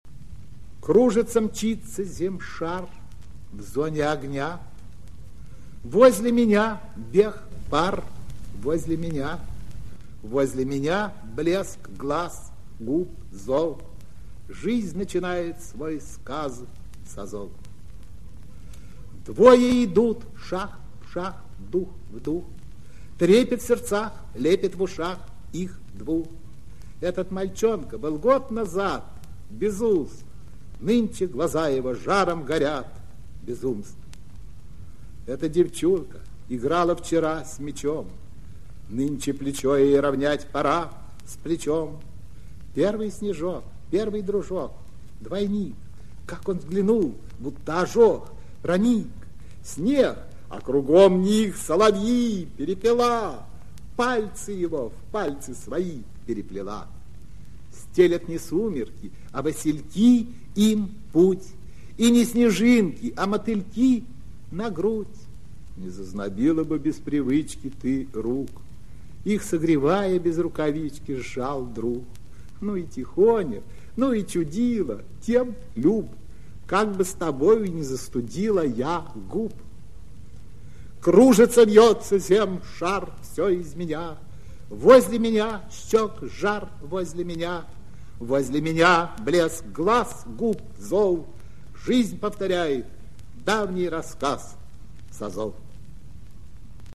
Aseev-Dvoe-idut-chitaet-avtor-stih-club-ru.mp3